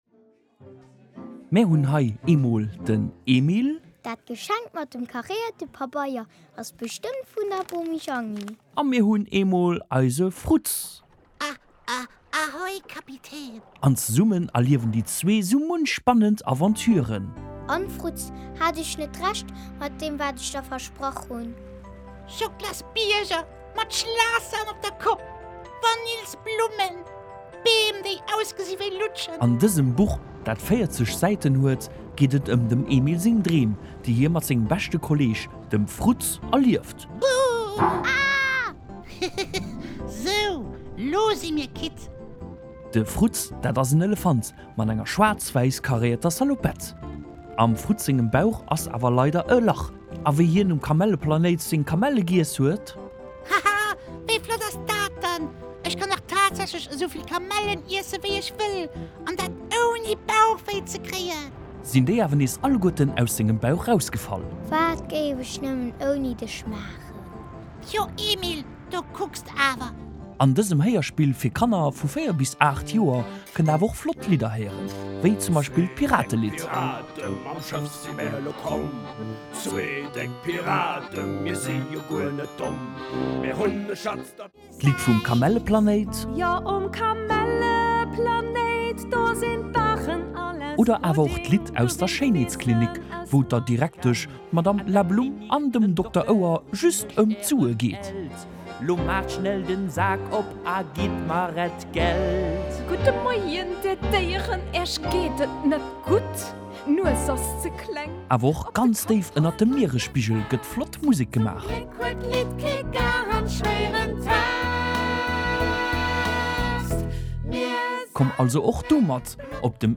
Et ass awer vill méi wéi ‚nëmmen ee Buch’ : ee flott Héierspill vun 33 Minutten, mat flotte Lidder, an enger spannender Geschicht 😊